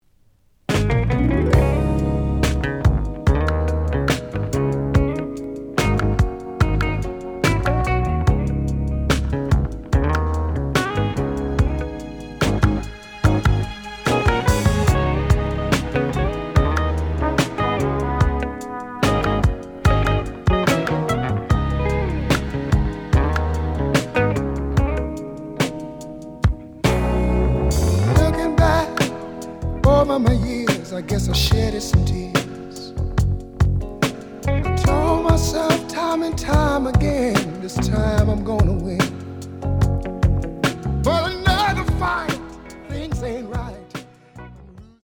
The audio sample is recorded from the actual item.
●Genre: Soul, 80's / 90's Soul
Slight edge warp. But doesn't affect playing. Plays good.)